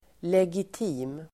Ladda ner uttalet
legitim adjektiv, legitimate Uttal: [legit'i:m] Böjningar: legitimt, legitima Synonymer: berättigad, laglig, rättfärdigad, tillåten Definition: lagligt berättigad Exempel: legitima krav (legitimate demands)